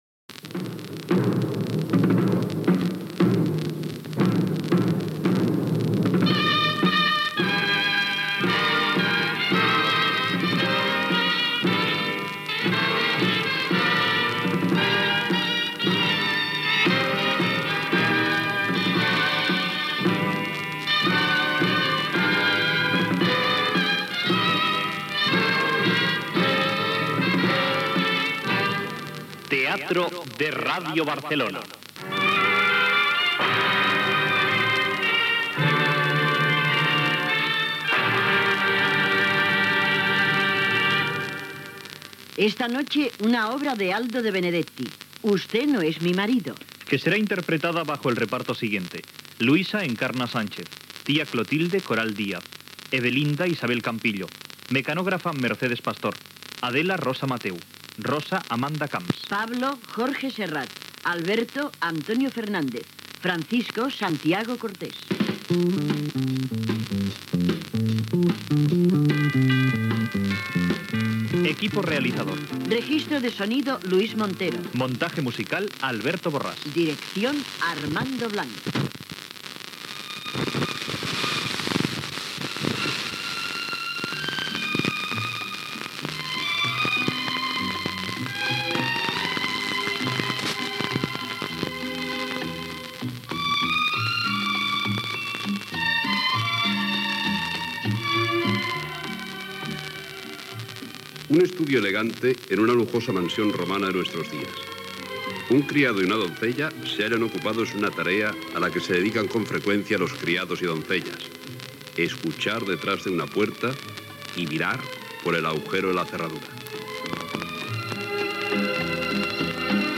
Adaptació radiofònica de l'obra "Usted no es mi marido" d'Aldo de Benedetti. Careta (veus de Josep Cuní), situació de l'obra, diàleg entre els servents, trucada telefònica, la senyora de la casa no reconeix al seu marit, el metge arriba Gènere radiofònic Ficció